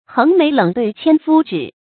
橫眉冷對千夫指 注音： ㄏㄥˊ ㄇㄟˊ ㄌㄥˇ ㄉㄨㄟˋ ㄑㄧㄢ ㄈㄨ ㄓㄧˇ 讀音讀法： 意思解釋： 比喻以憤恨和輕蔑的態度對待敵人的攻擊 出處典故： 魯迅《自嘲》：「 橫眉冷對千夫指 ，俯首甘為孺子牛。」